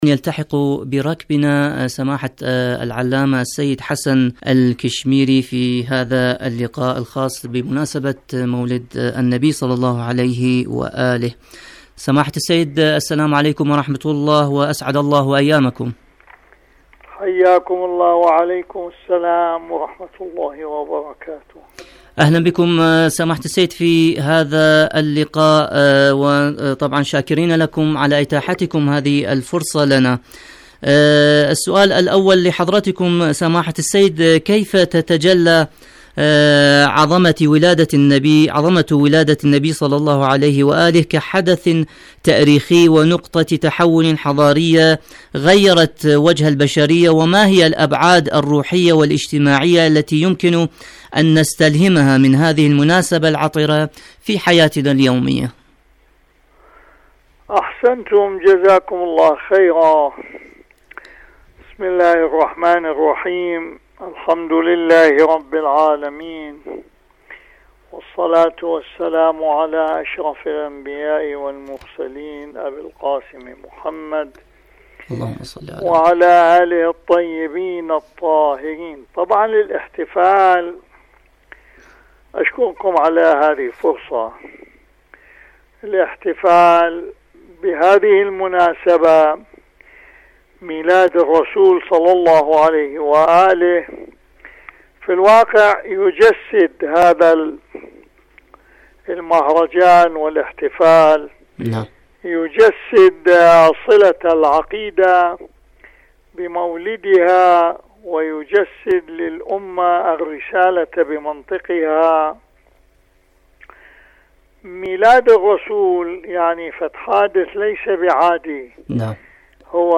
برامج إذاعة طهران العربية مقابلات إذاعية